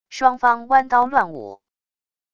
双方弯刀乱舞wav音频